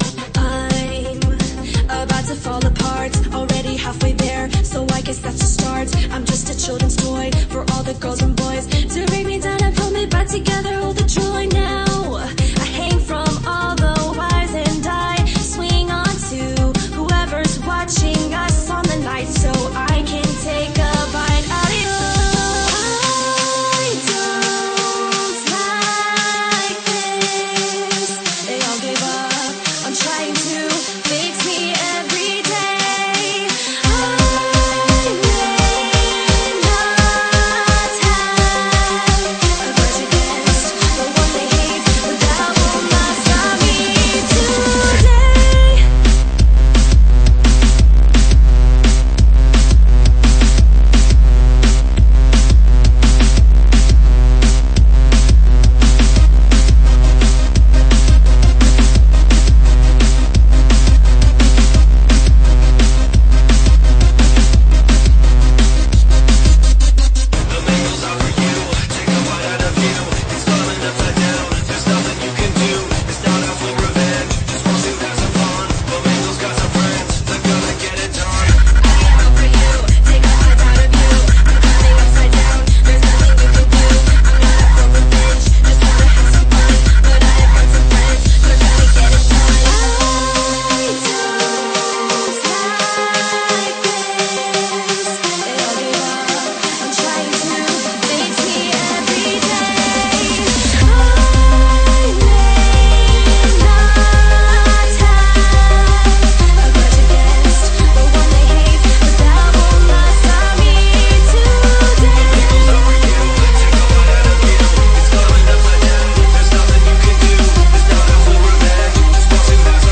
BPM86-172
Audio QualityCut From Video